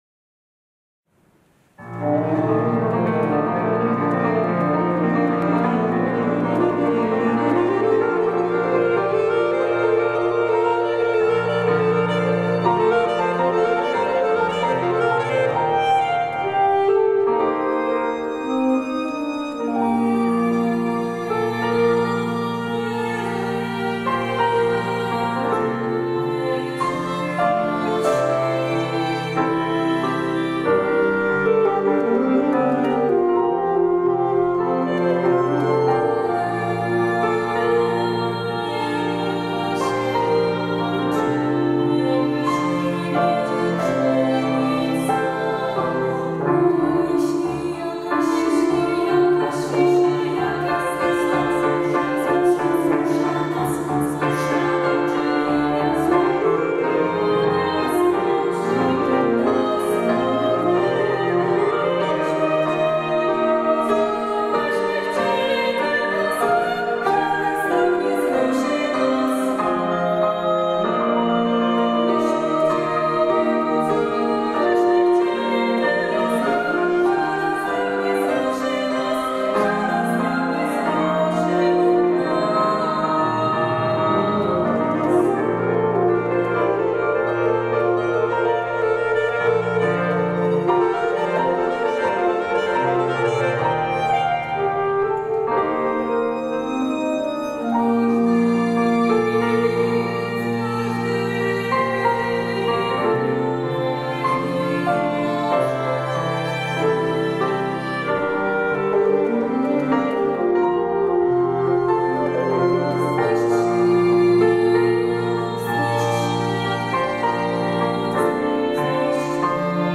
sopran
skrzypce
saksofon
puzon
fortepian
7 lipca 2019, kościół p.w. Nawiedzenia Najświętszej Maryi Panny w Zaborowie